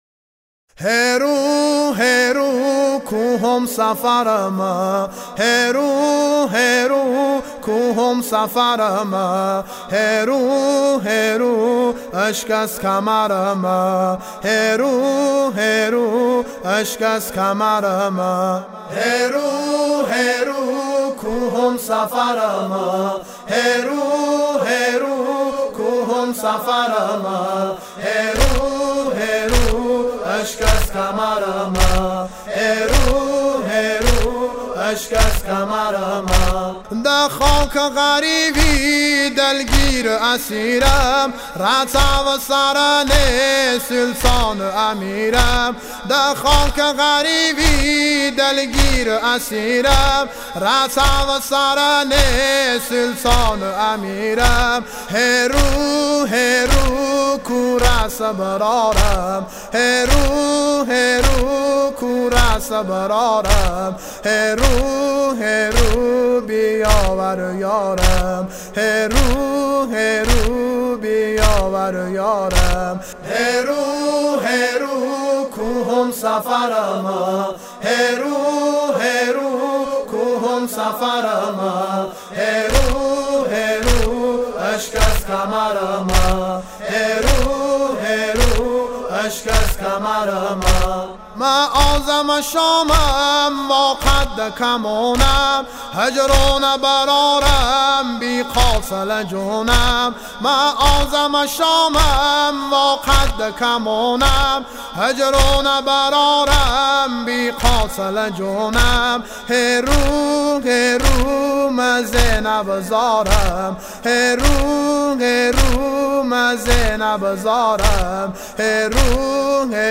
مداحی و نوحه لری